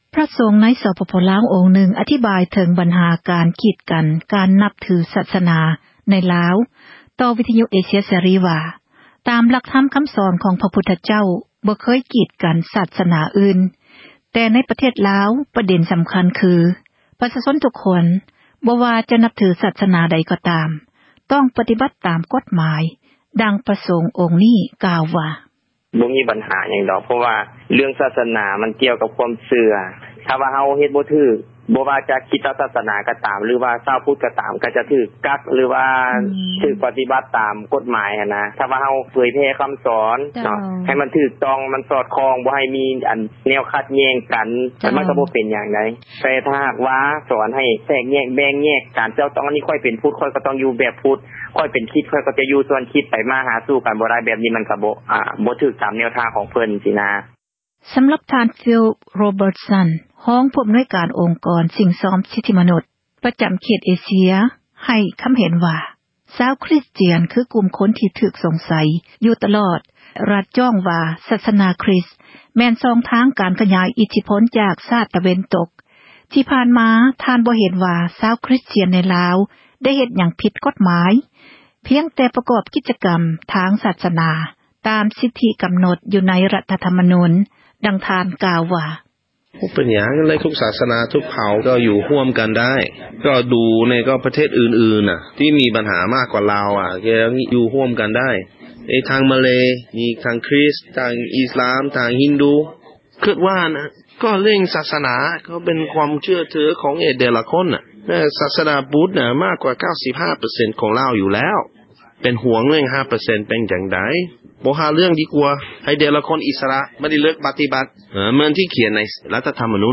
ພຣະສົງ ລາວ ຮູປນຶ່ງ ກ່າວເຖິງ ຫລັກ ຄຳສອນ ຂອງ ພຸທທະ ວ່າ ບໍ່ໃຫ້ ກີດກັນ ແບ່ງແຍກ ຜູ້ ທີ່ຖື ສາສນາ ອື່ນ, ແລະ ທ່ານ ໄດ້ກ່າວ ຕໍ່ ເອເຊັຽ ເສຣີ ເຖິງ ການນັບຖື ສາສນາ ໃນລາວ ວ່າ, ປະເດັນ ສຳຄັນ ຄື: ຄົນລາວ ທຸກຄົນ ບໍ່ວ່າຈະ ນັບຖື ສາສນາ ໃດ ກໍຕາມ ຕ້ອງ ປະຕິບັດ ຕາມ ກົດຫມາຍ.